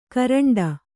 ♪ karaṇḍa